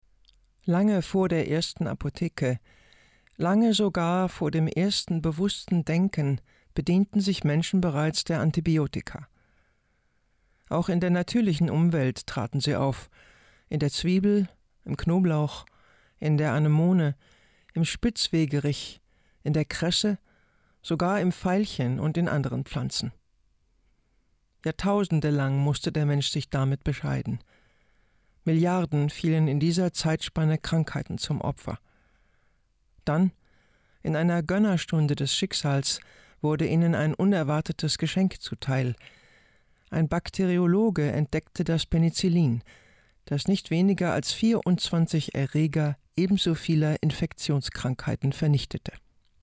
markant
Alt (50-80)
Doku